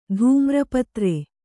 ♪ dhūmra patre